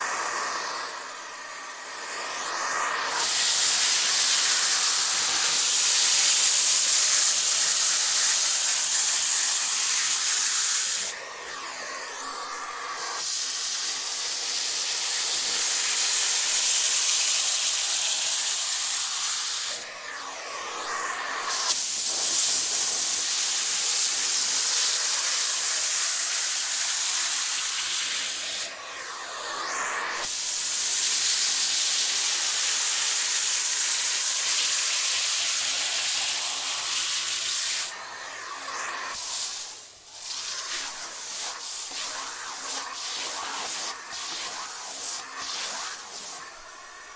吸尘器的吸气声
描述：使用最窄的端盖和最高的功率。听起来像牙医工具，但它是一个普通的吸尘器。
标签： 牙医 真空 吸吮 吸尘器
声道立体声